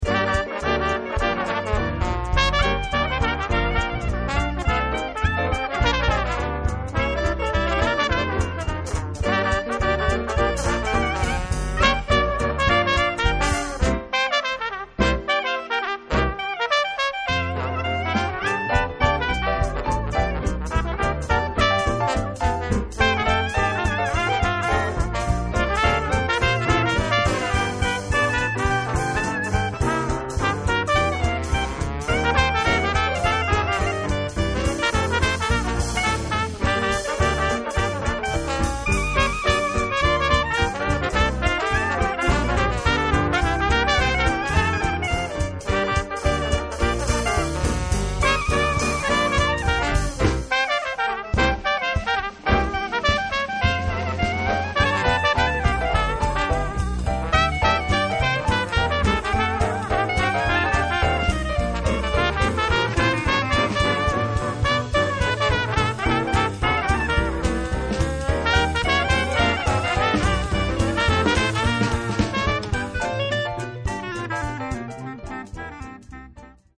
• Outstanding traditional Dixieland jazz band in Bristol
Lively, energetic and simply oozing class, this exceptional trad jazz band successfully captures the Mardi Gras spirit of The Big Easy, the birthplace of jazz, at its very best.
The seven-strong full band is comprised of trumpet, clarinet, trombone, guitar, piano, bass and drums, although the line-up can easily be altered to suit your event.